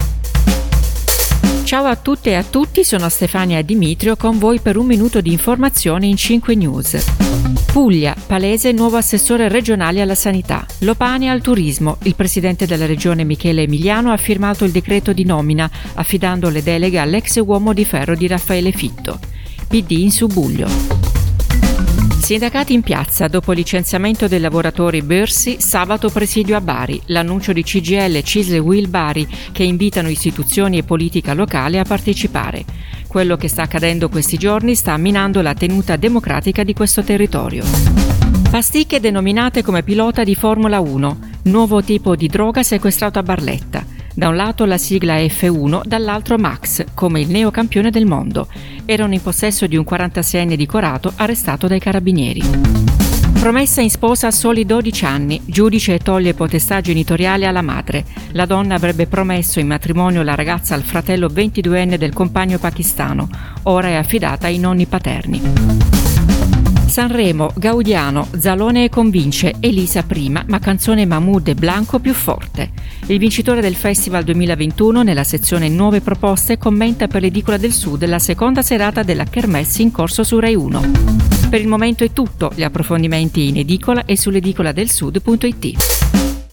Ep108 – GR13 Cinque News in un minuto
Giornale radio alle ore 13